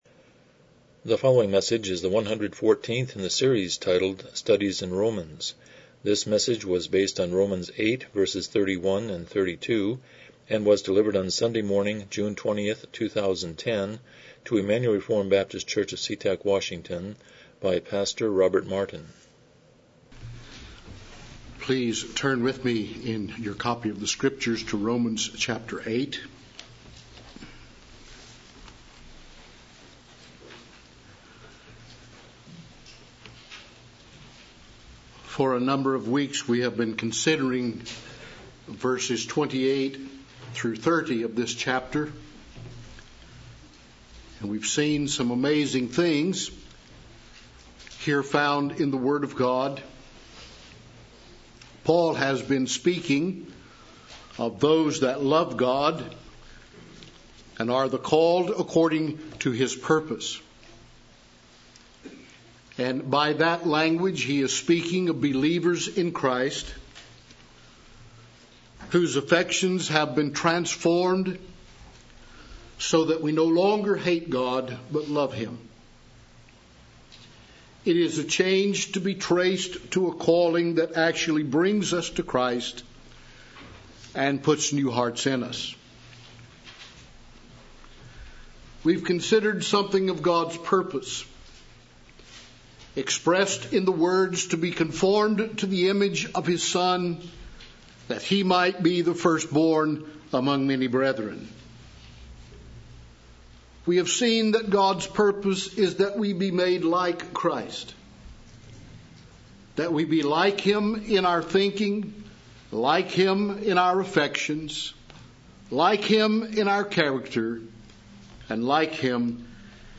Romans 8:31-32 Service Type: Morning Worship « 100 Chapter 19.3